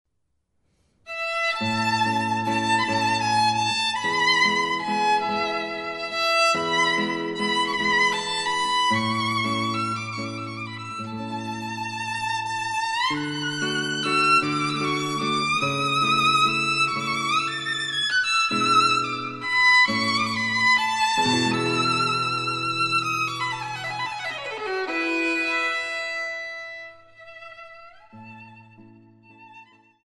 小提琴
吉他
【所屬類別】 XRCD唱片　　古典音樂